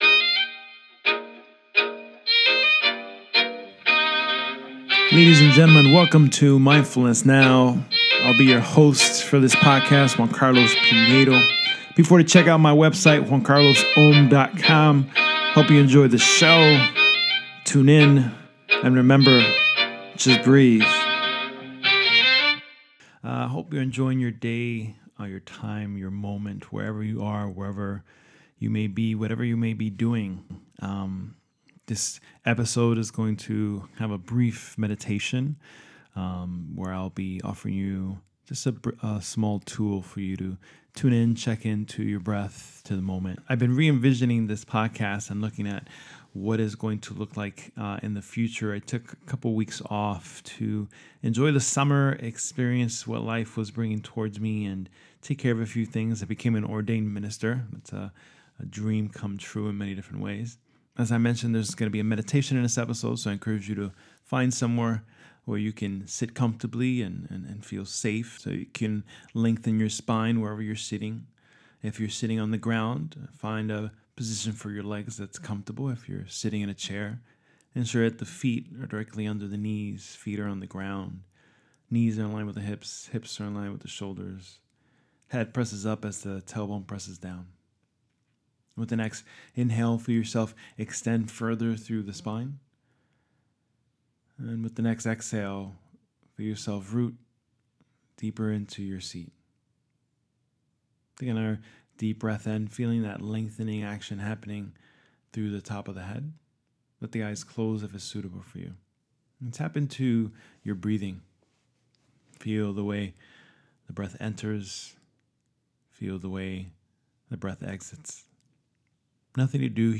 Episode 4 Awareness Meditation